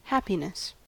happiness-us.mp3